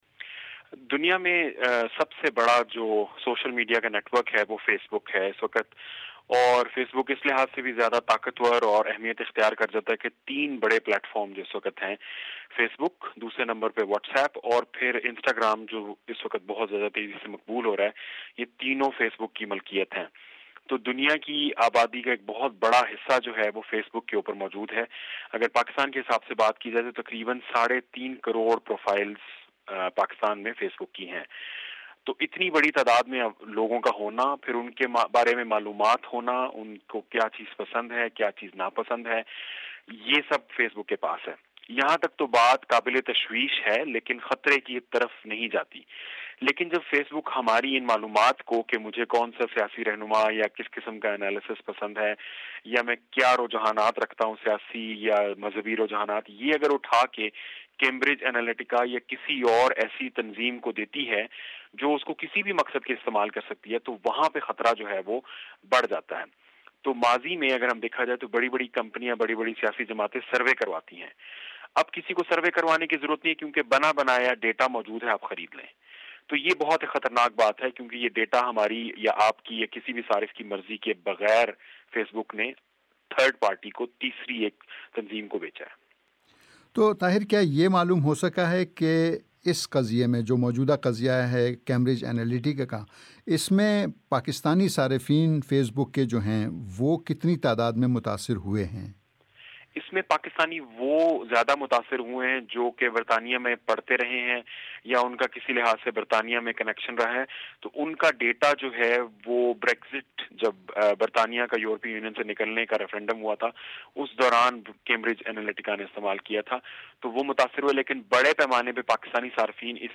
Can facebook affect Pakistani elections? BBC Urdu radio program discusses the issue.